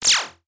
zap.mp3